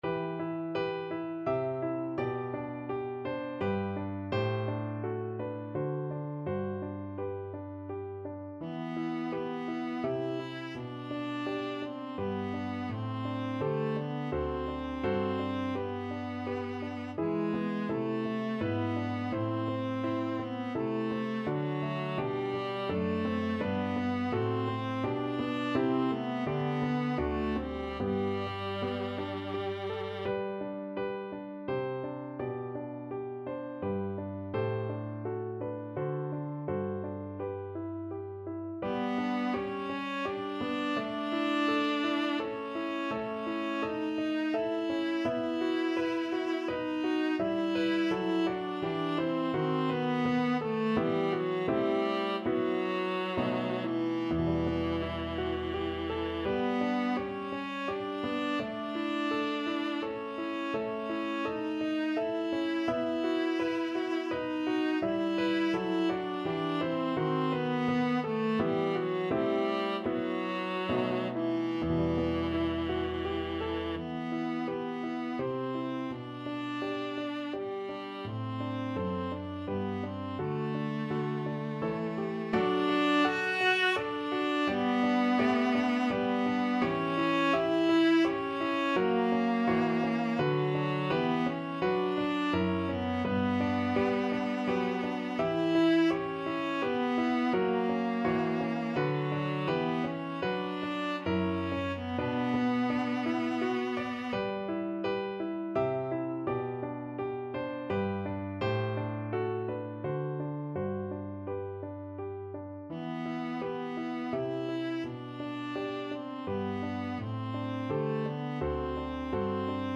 Viola
Italian Baroque composer.
E minor (Sounding Pitch) (View more E minor Music for Viola )
3/4 (View more 3/4 Music)
Allegretto grazioso = 84
Classical (View more Classical Viola Music)
sebben_crudele_VLA.mp3